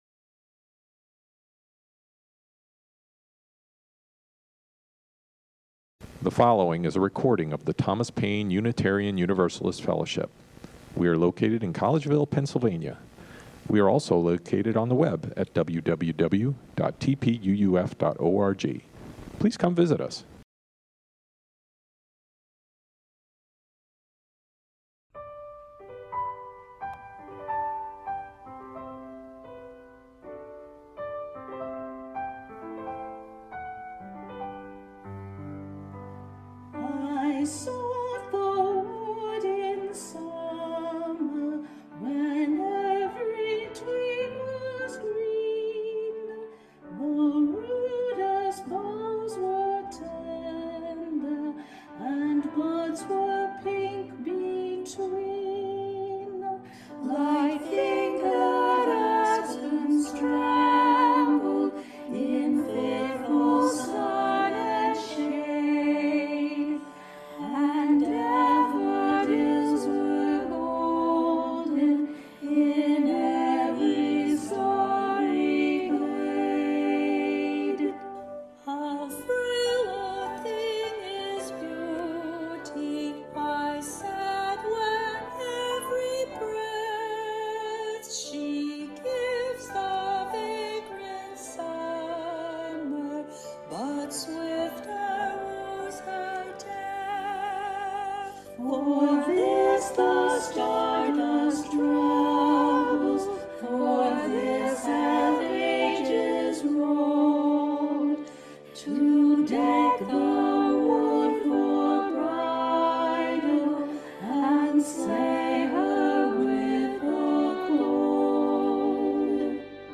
The natural world is all around us from the deer footing grass outside of the sanctuary to the caterpillar eating the parsley in your garden. Join us in this Sunday service to explore the gifts the natural has to offer … Continue reading Small Things Considered